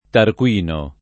Tarquinio [ tark U& n L o ]